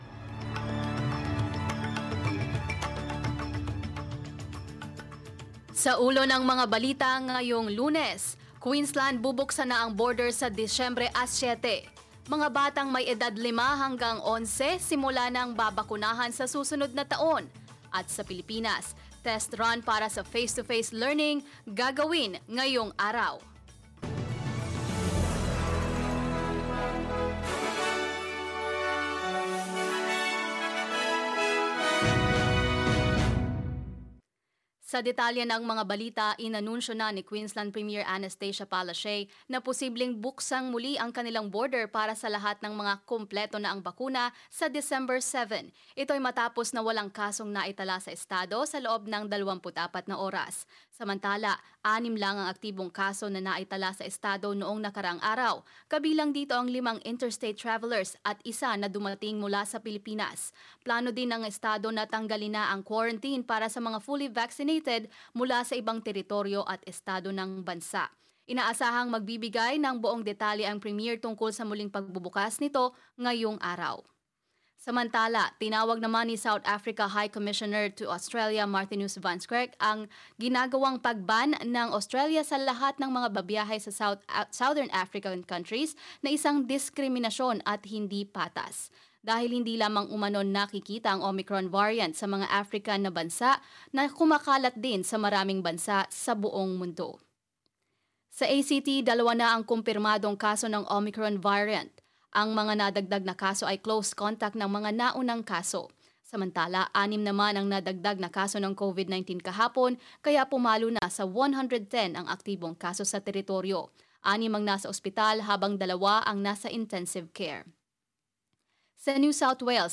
filipino_news_dec_6.mp3